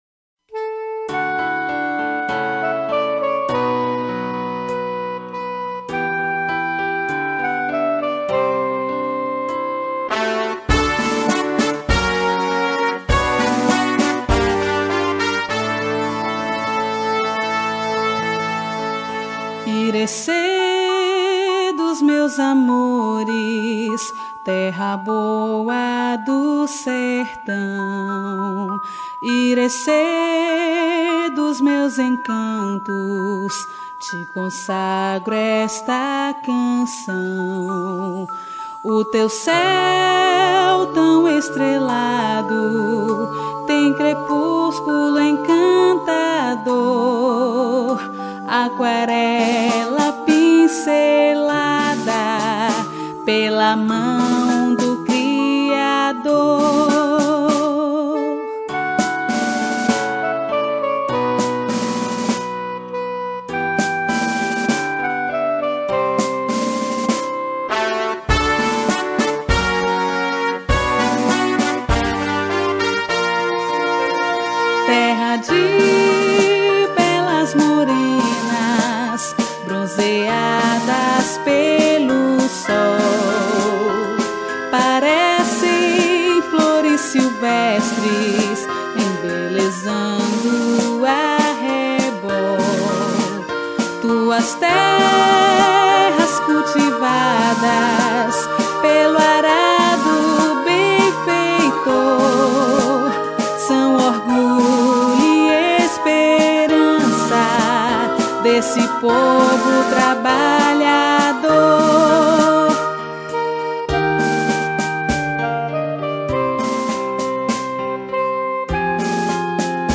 HINO-DO-MUNICIPIO-DE-IRECE.mp3